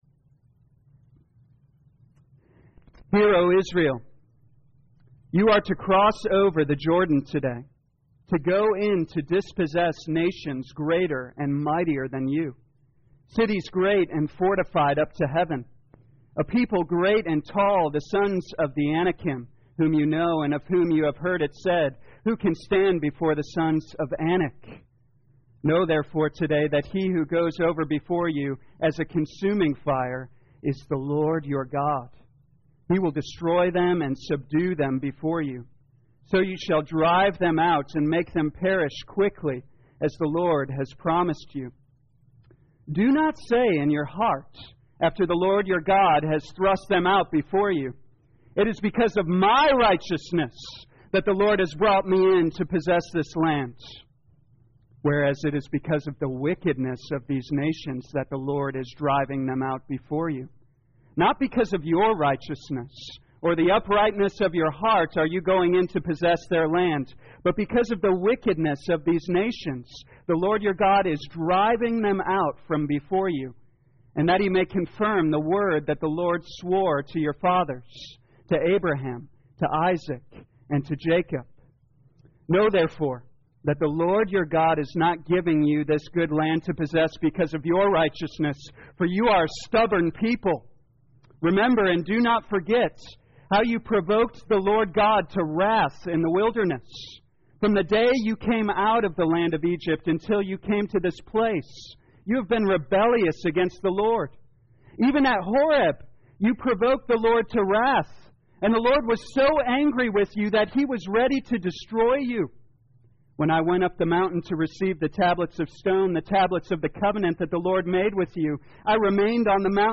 2022 Deuteronomy The Law Evening Service Download: Audio Notes All sermons are copyright by this church or the speaker indicated.